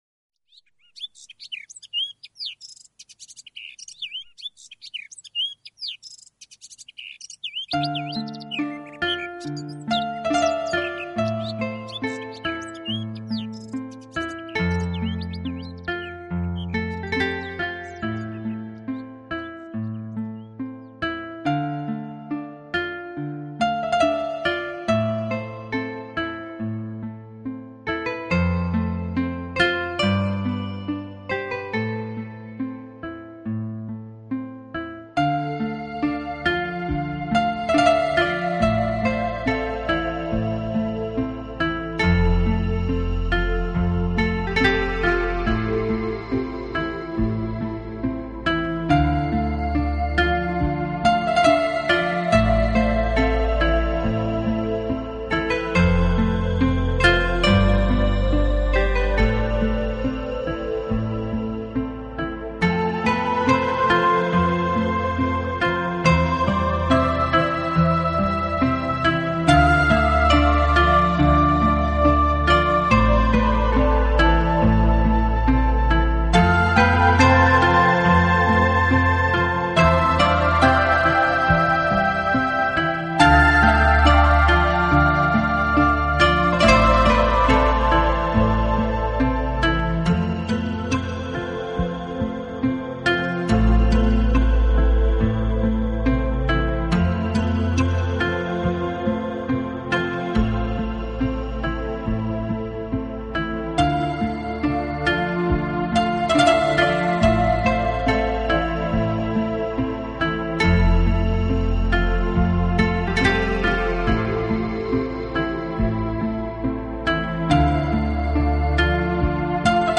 【纯音乐】
本輯設定的音樂以氣勢開闊、清涼乾淨的曲式為主，引領您進入屬於雲端的